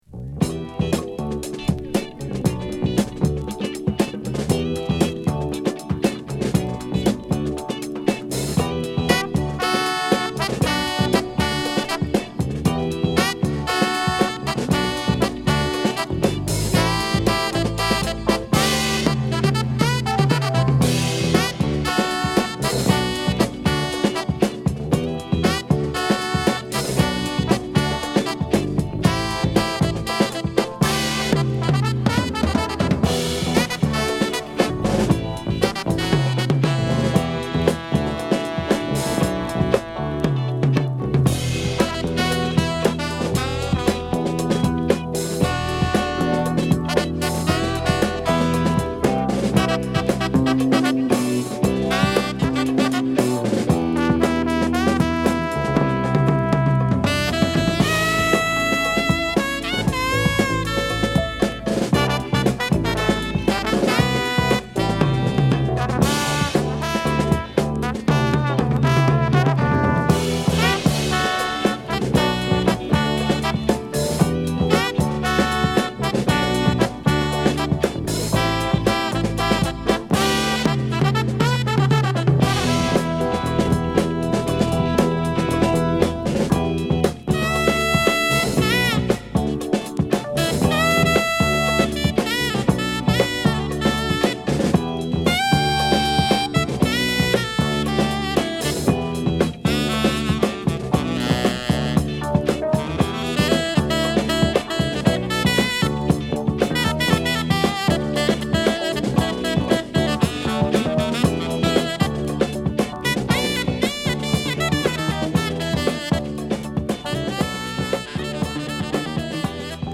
BPM早めのダンサブルなトラックを満載。